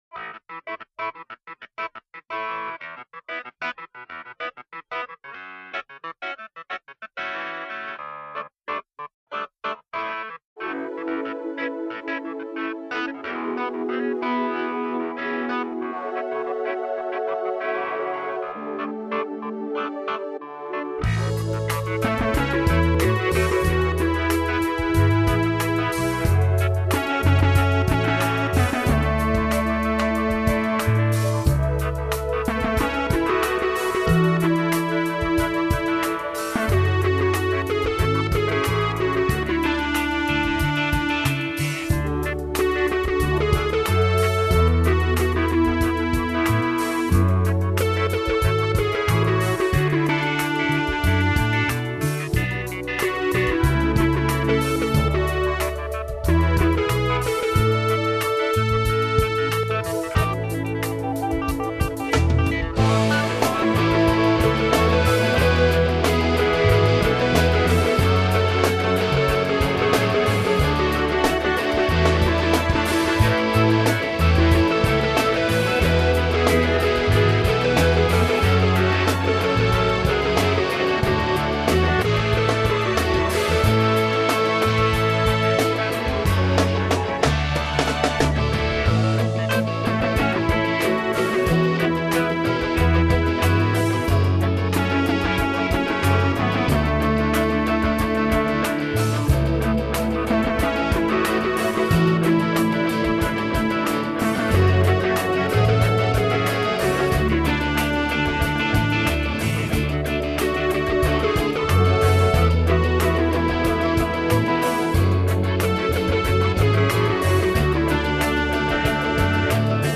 praise and worship song
My backing gets noisy in the refrain as it should.